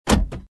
Звук открывающегося капота